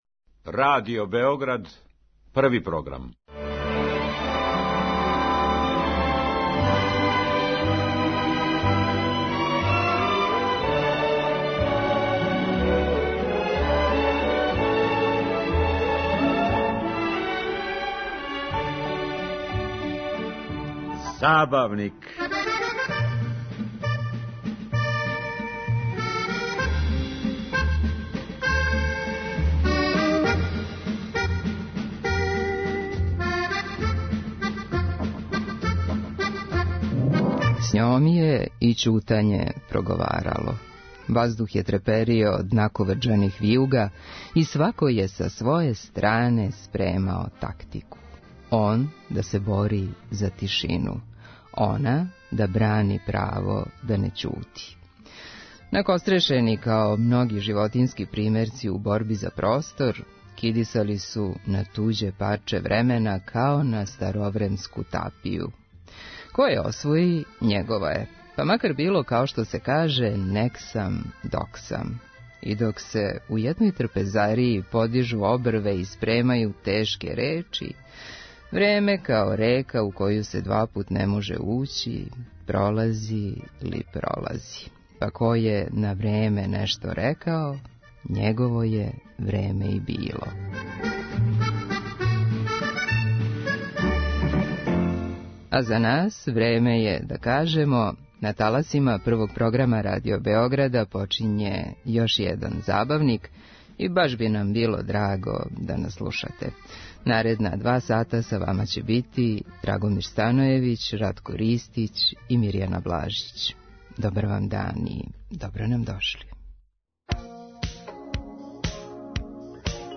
Евергрин музика се подразумева.